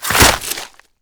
flesh1.wav